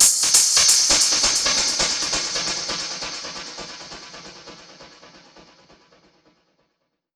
Index of /musicradar/dub-percussion-samples/134bpm
DPFX_PercHit_E_134-06.wav